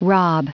Prononciation du mot rob en anglais (fichier audio)